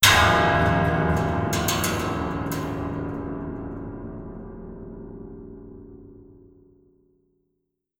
Jumpscare_09.wav